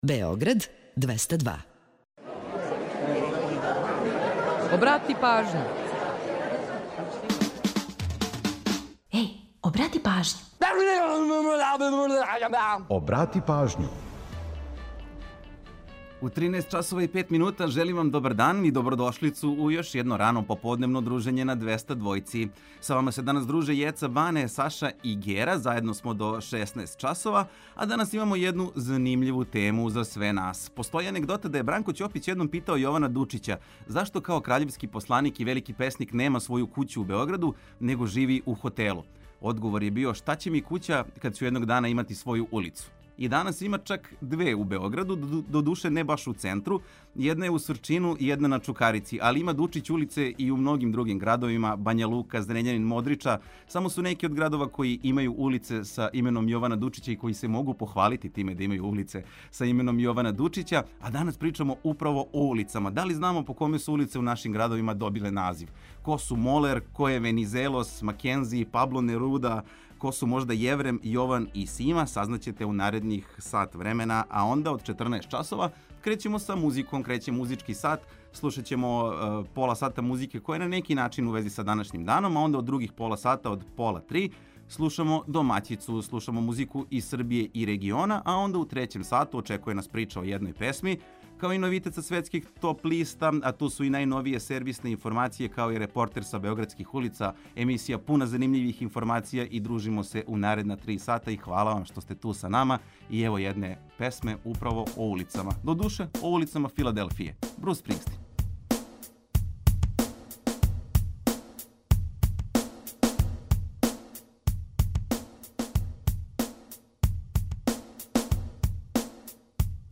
У наставку емисије, подсетићемо вас на важне догађаје у поп-рок историји који су догодили на данашњи дан. Ту је и пола сата резервисаних за домаћицу, музику из Србије и региона, као и прича о једној песми, и низ актуелних занимљивости и важних информација.